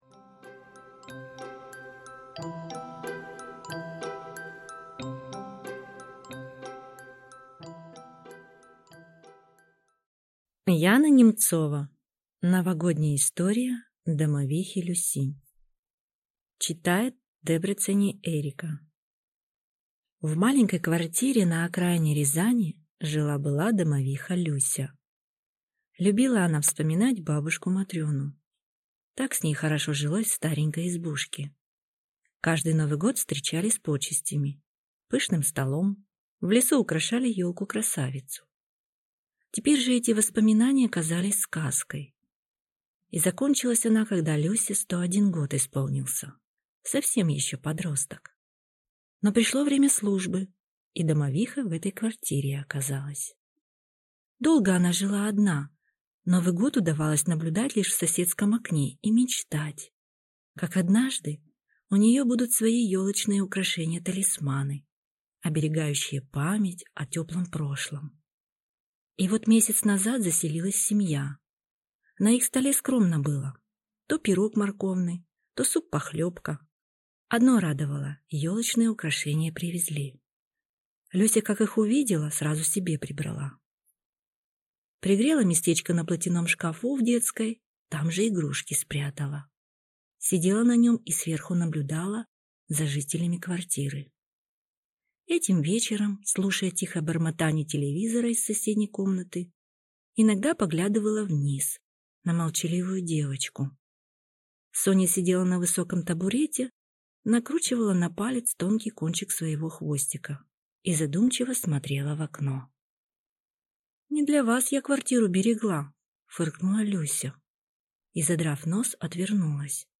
Аудиокнига Новогодняя история домовихи Люси | Библиотека аудиокниг